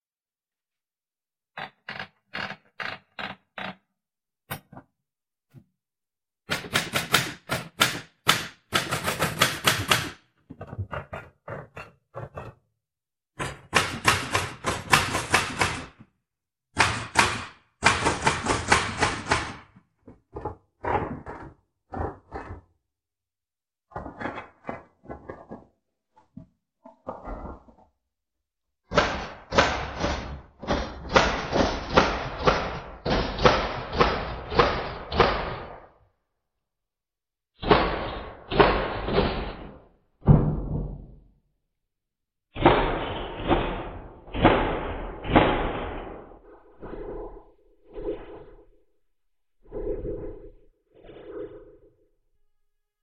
描述：当打字机成为枪，享受。已录制了移动和音频节目编辑。
Tag: 打字机